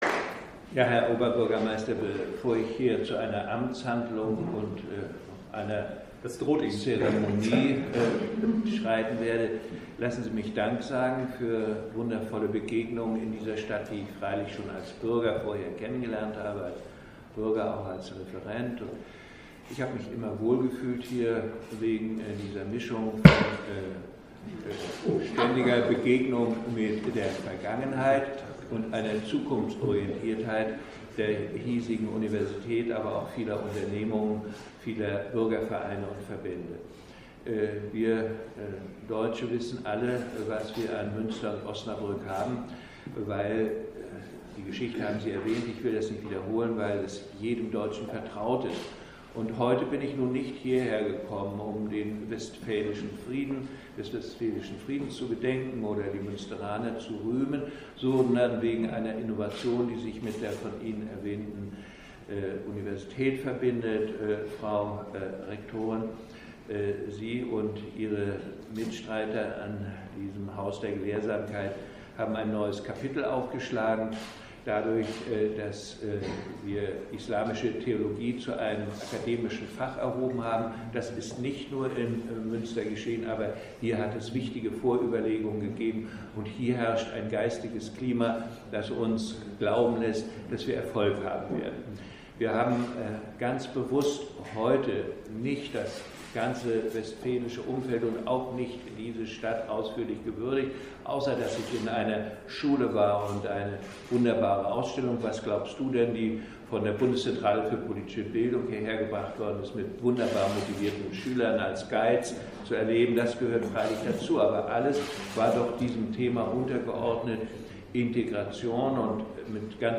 rede-gauck-friedensaal.mp3